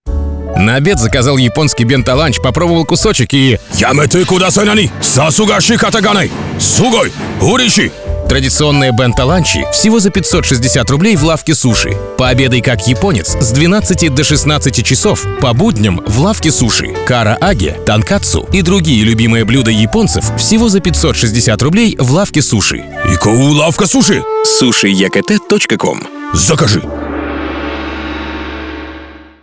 Примеры аудиороликов